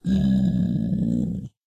Sound / Minecraft / mob / zombiepig / zpig2.ogg